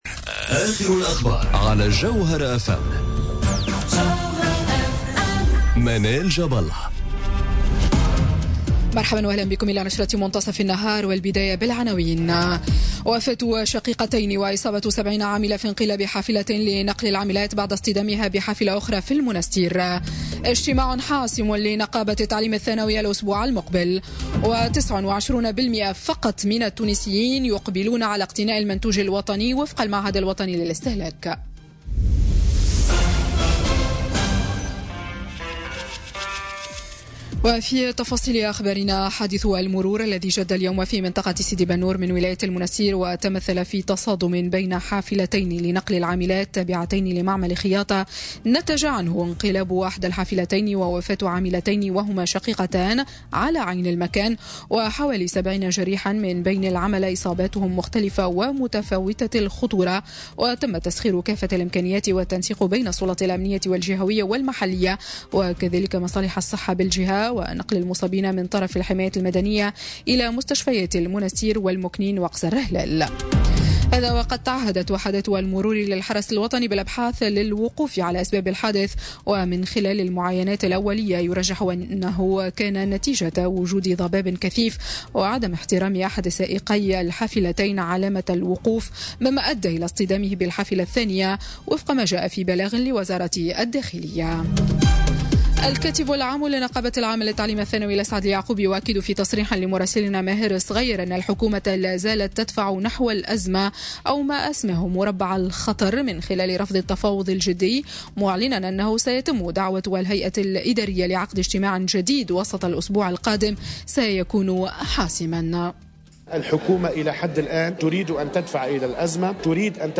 نشرة أخبار منتصف النهار ليوم السبت 7 أفريل 2018